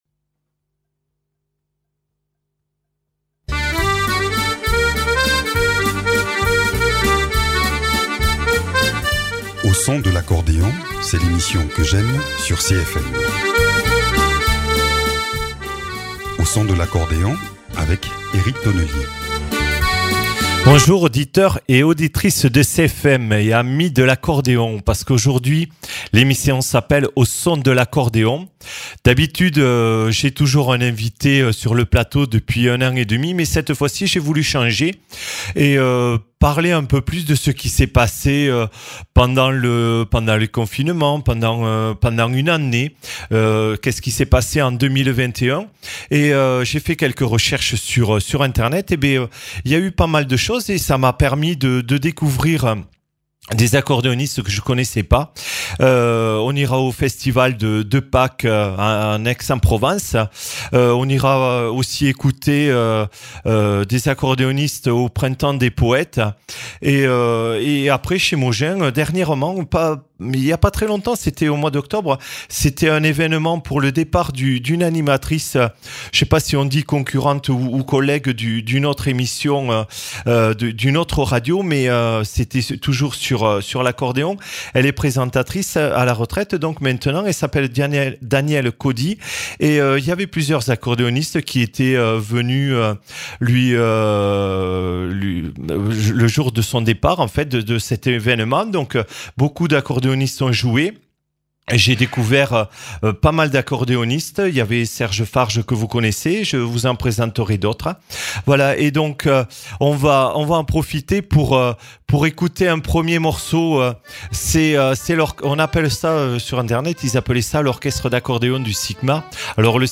Accordéon, saison 2021
Tour du monde au son actuel de l’accordéon pour une émission de découverte sans frontières aucune.